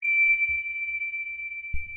sonarPingSuitFar2.ogg